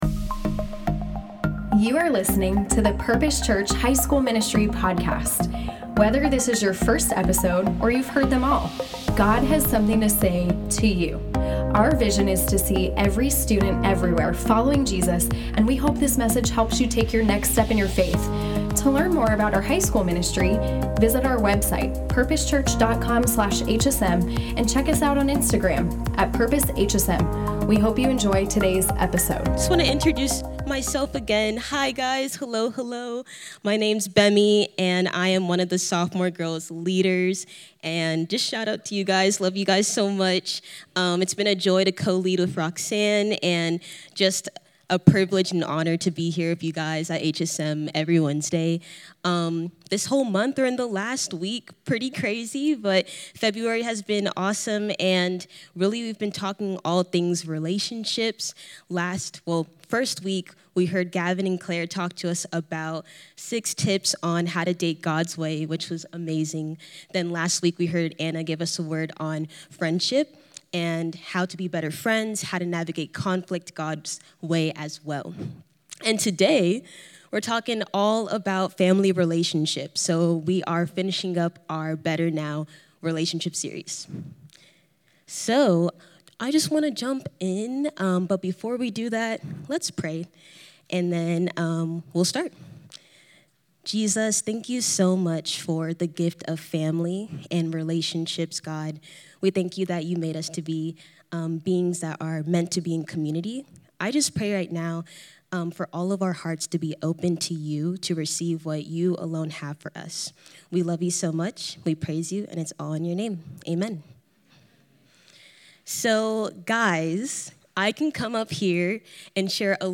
Loving your family can be pretty difficult at times but with the right heart posture and the Lord’s guidance we can gain wisdom and understanding on how to do so God’s way! We learned from today’s sermon through surrendering our full hearts to Jesus we can be peacemakers and set apart within our families.